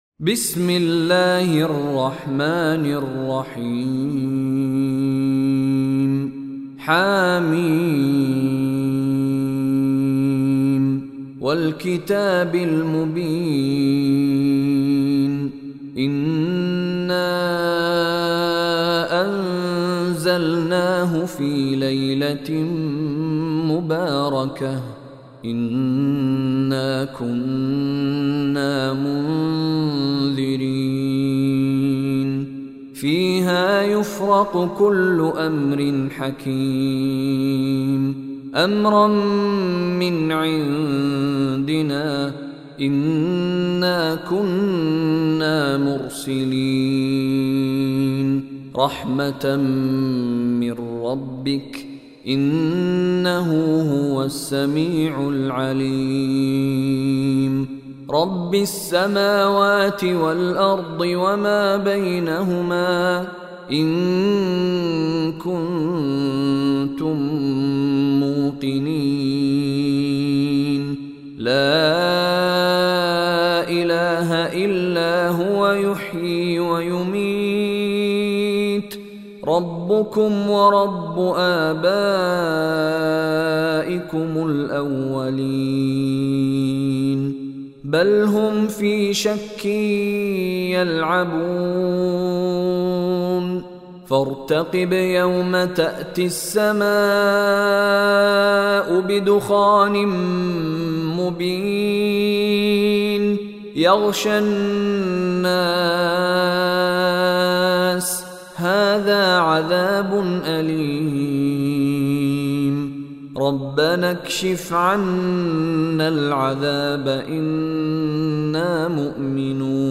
Surah Ad-Dukhan Recitation by Mishary Rashid
Surah Ad-Dukhan is 44 chapter of Holy Quran. Listen online mp3 tilawat / recitation of Surah Ad-Dukhan in the voice of Sheikh Mishary Rashid Alafasy.